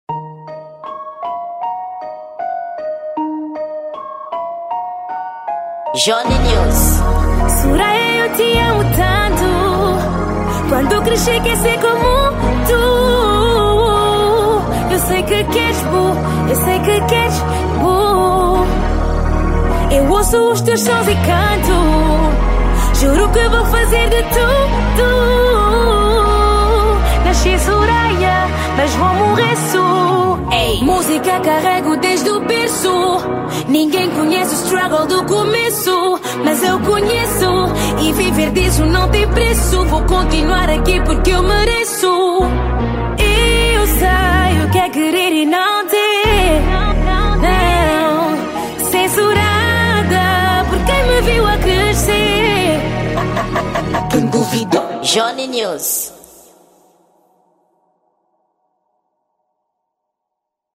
Gênero: Soul